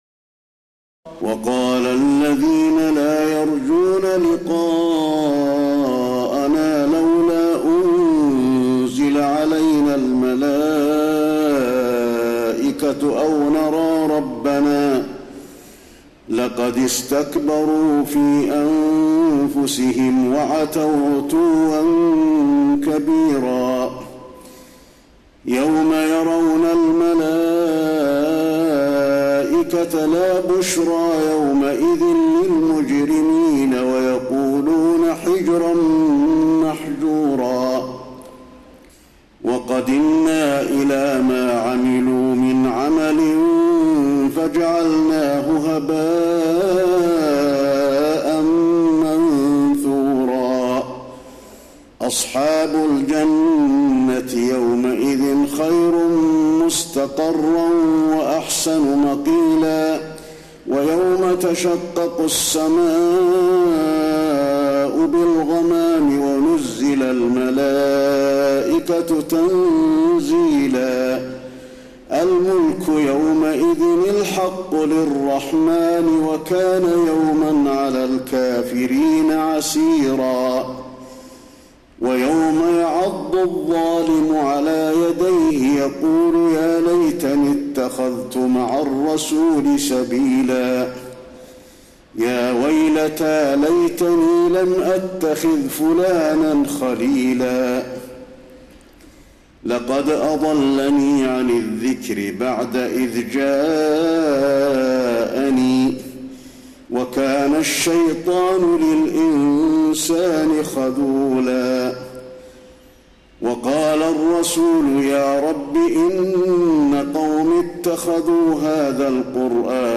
تراويح الليلة الثامنة عشر رمضان 1432هـ من سورتي الفرقان (21-77) و الشعراء (1-104) Taraweeh 18 st night Ramadan 1432H from Surah Al-Furqaan and Ash-Shu'araa > تراويح الحرم النبوي عام 1432 🕌 > التراويح - تلاوات الحرمين